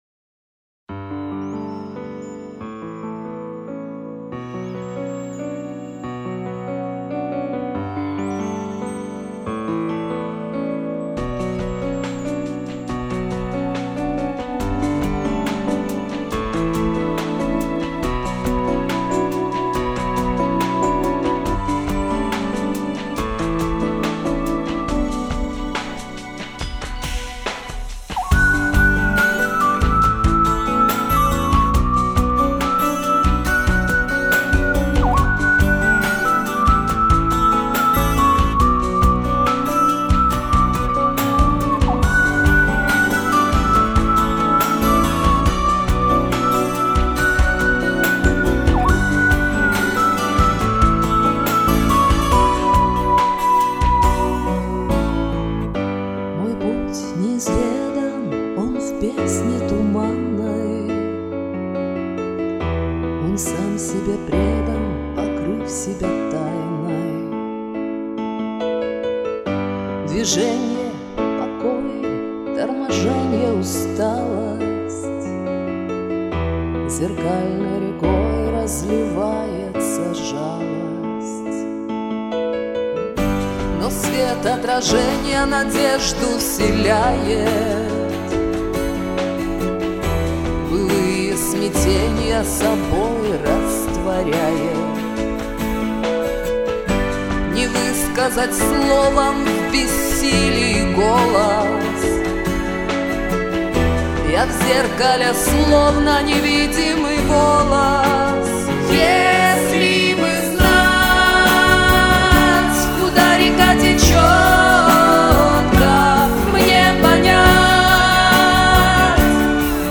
Куплеты и припевы - красочны!